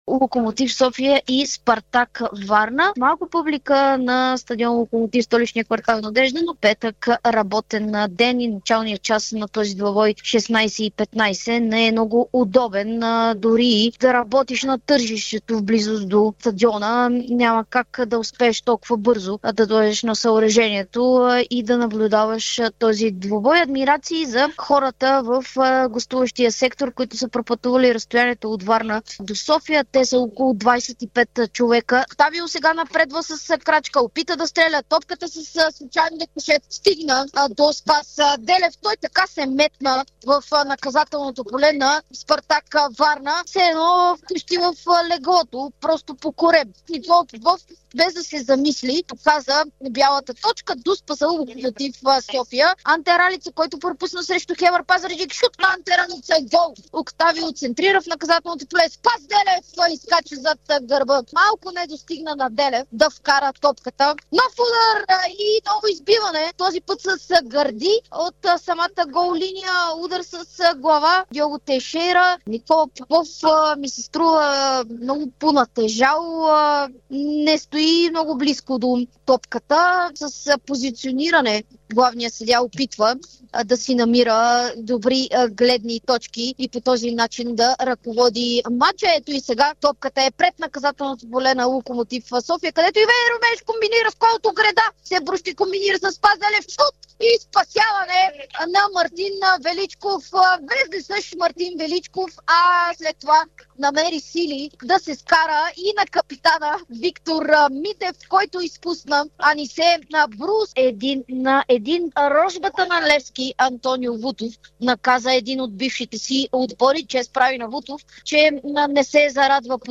Какво чухме от Дарик от родните футболни терени от изминалия 26-и кръг в Първа лига с битките, головете, китайските хронометри, драмите и пилето с ориз през очите на коментаторите на двубоите след завръщането на клубния футбол след международната пауза.